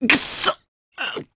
Roi-Vox_Damage_jp_01.wav